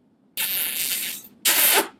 kiss.ogg